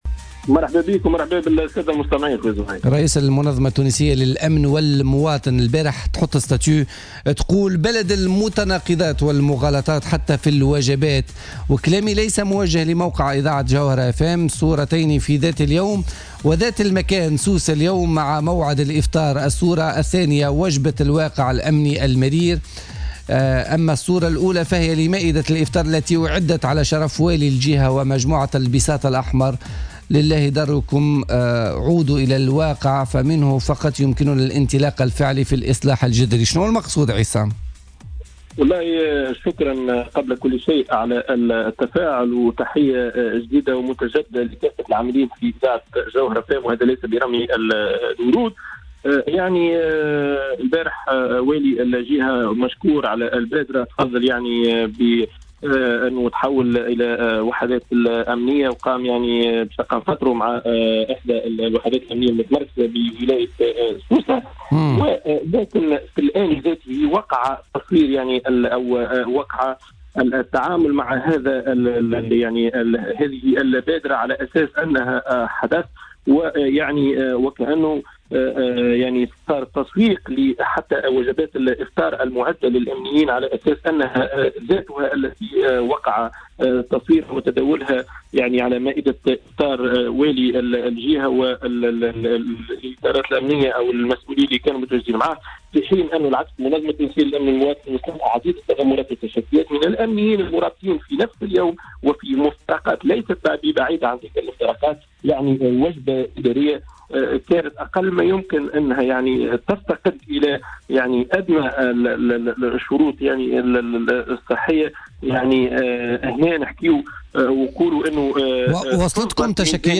في مداخلة له في برنامج الحدث